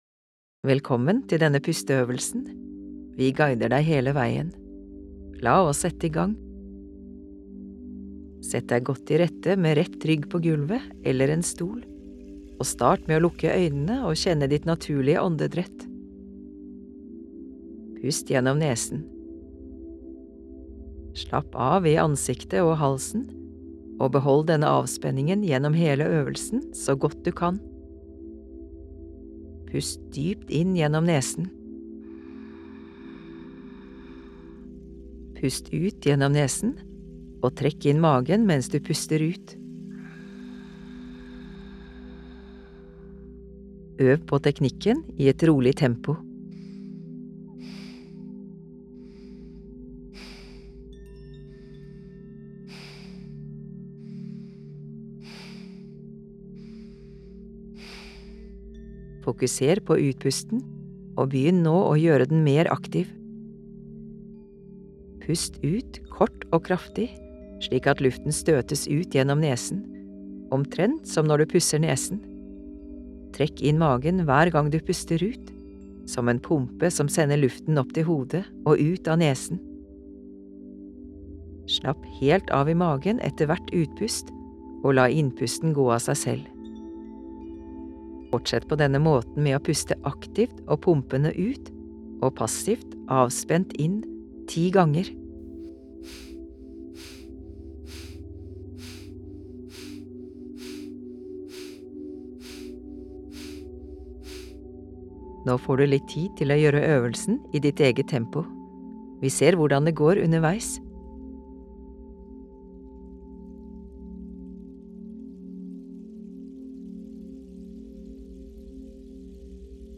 Ildpust – pusteøvelse med lydguide
• Først får du en intro til øvelsen.
• Deretter får du 2 min til å fortsette pusteøvelsen i ditt eget tempo.
• Til slutt får du 2 min til å kjenne på virkningen av øvelsen.
Pusteøvelsen består av små, eksplosive utpust som presser luften ut av lungene, mens innpusten er passiv.